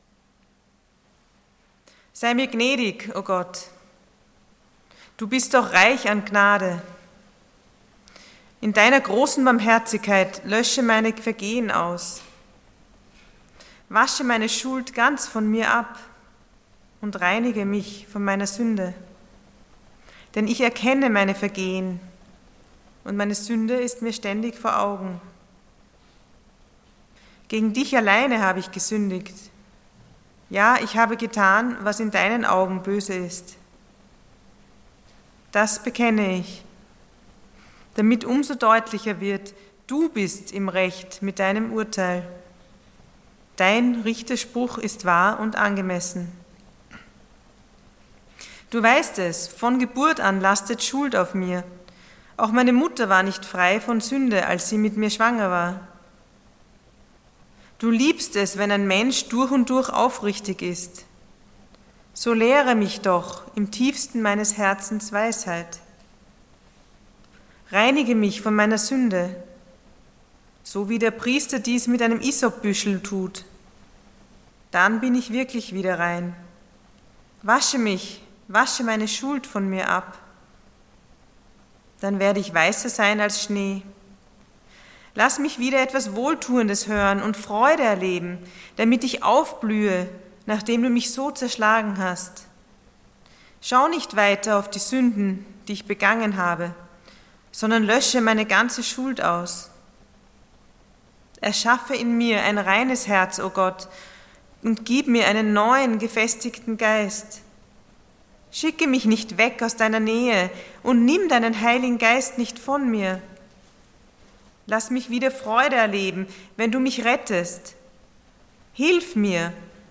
Passage: Psalm 51:1-21 Dienstart: Sonntag Morgen